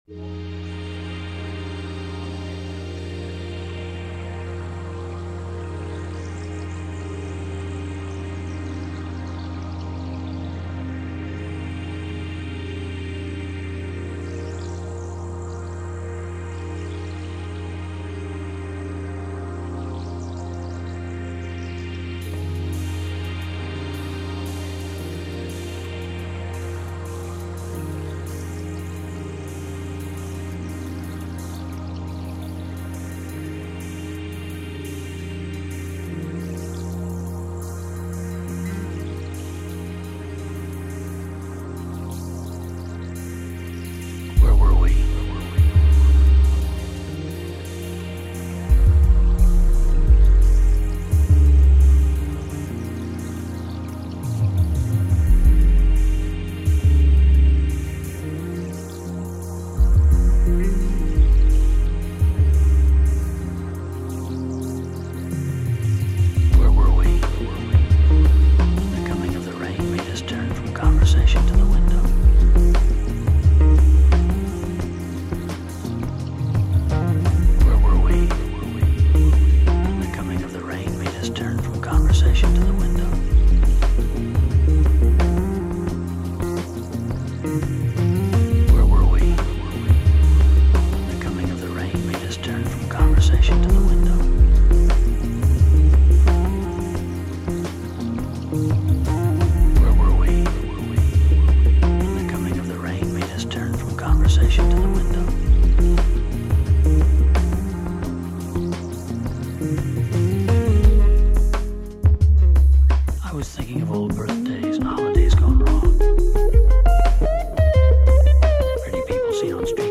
Here are electronic songs I have recorded.